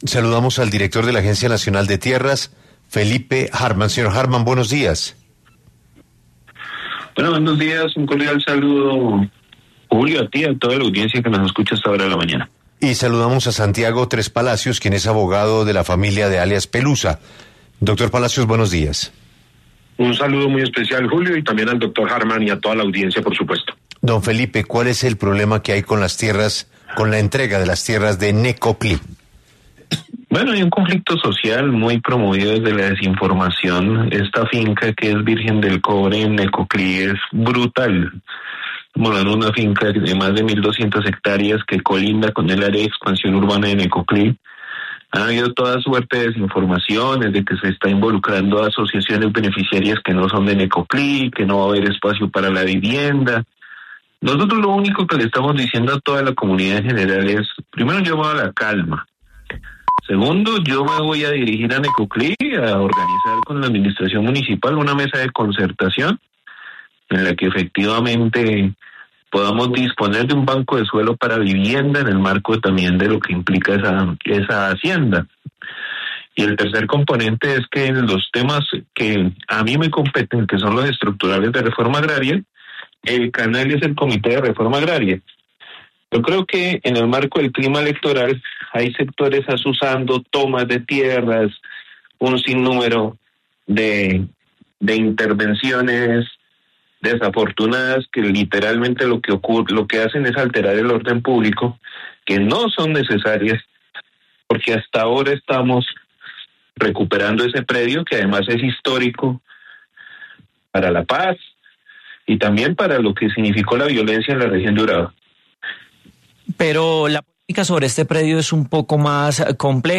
¿Fue irregular la entrega de tierras de un predio vinculado al paramilitarismo en Necoclí? Debate